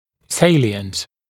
[‘seɪlɪənt][‘сэйлиэнт]заметный, бросающийся в глаза, выдающийся, выступающий